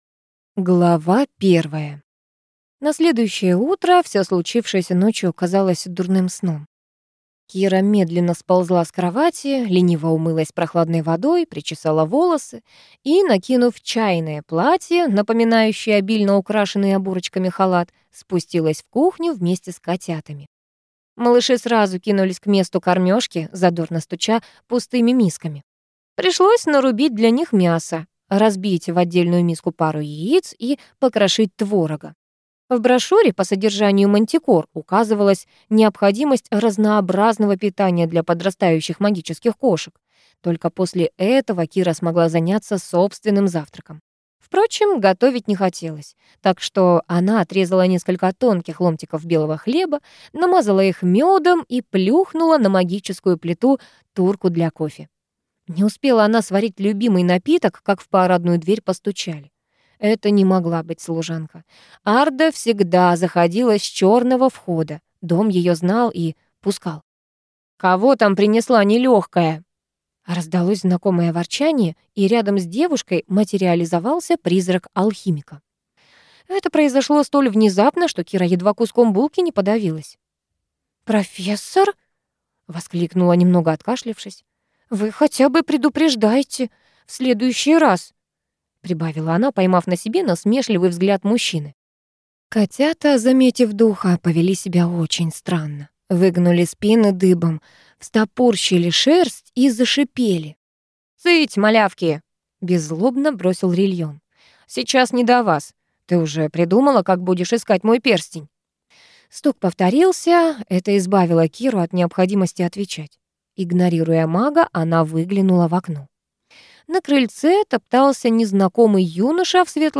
Аудиокнига Аферистка по призванию. Книга II | Библиотека аудиокниг